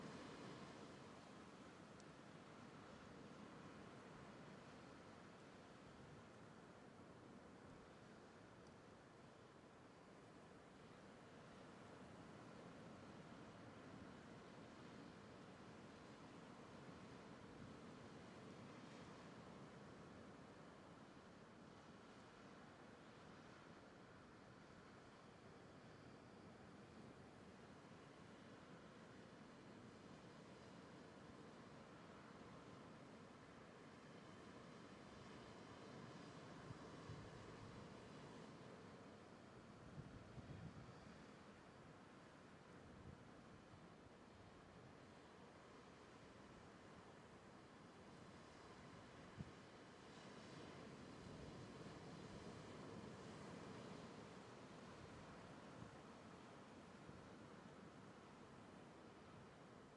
死亡的海岸 " MUIÑOS BEACH ORTF
Tag: 大洋 大海 沙滩 海浪